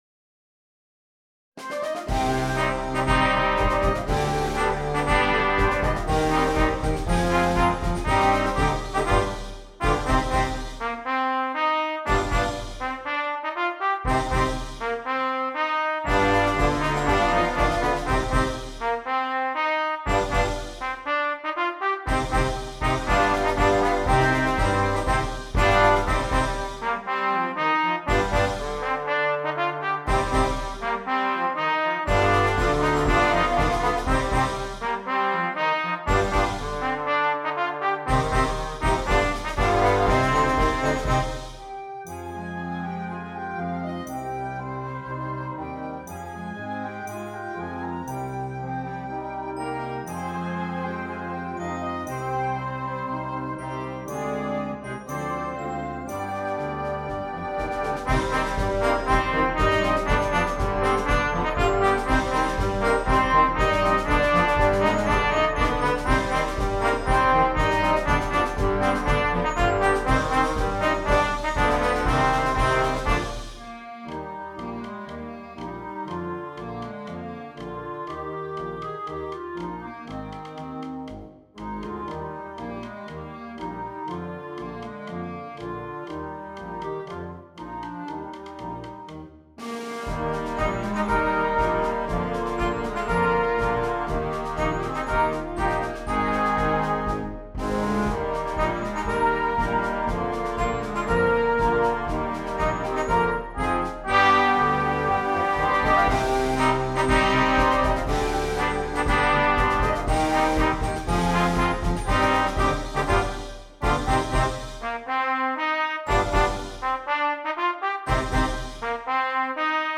Concert Band
a concert march